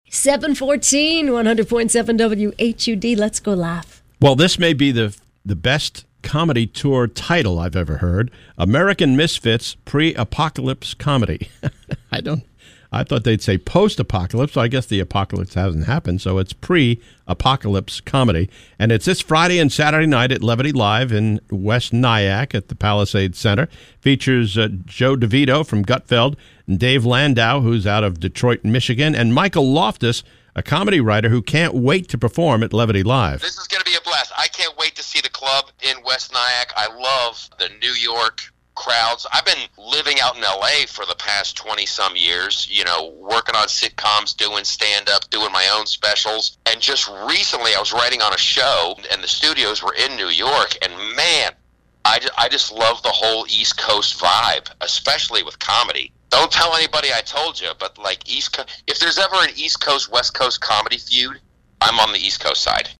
Comedian Michael Loftus at Levity Live 5-23-23